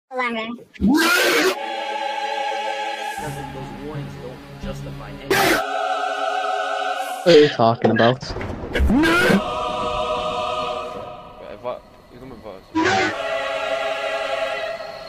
Neck hurt invincible Meme Sound Effect
The Neck hurt invincible Meme Sound Effect sound button is from our meme soundboard library